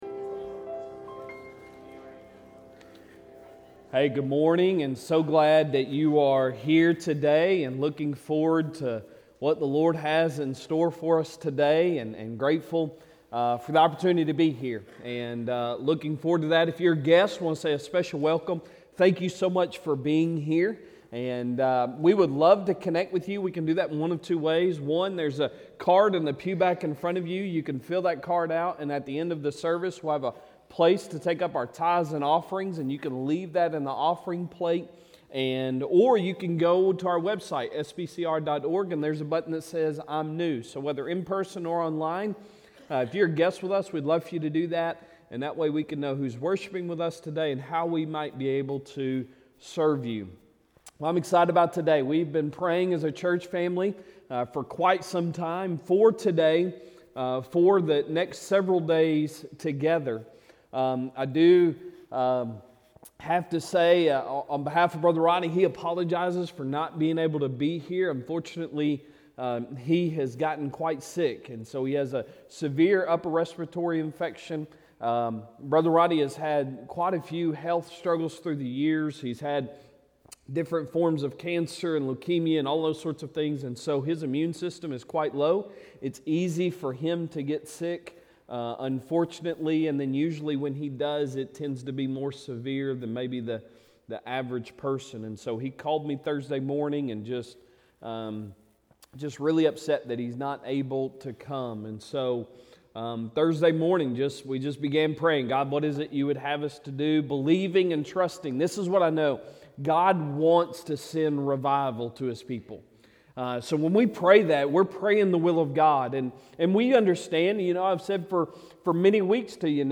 Sunday Sermon April 2, 2023